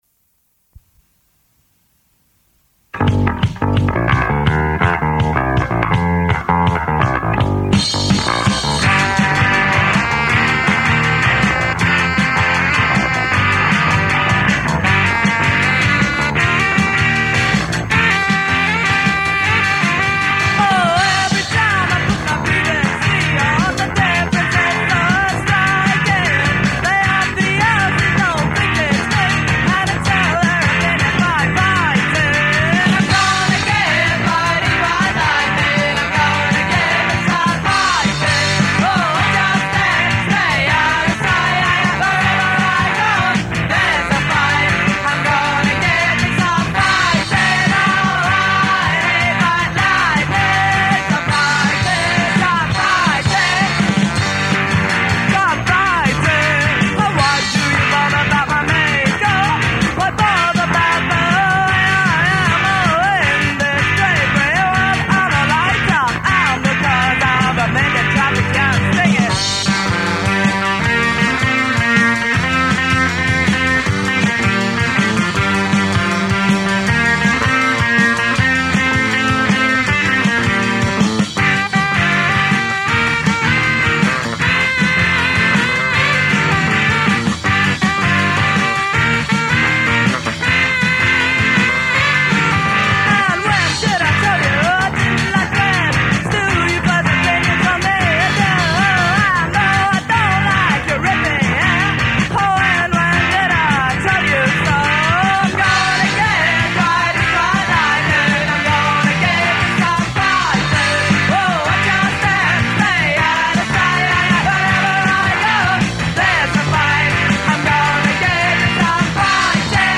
Sax – Gitarr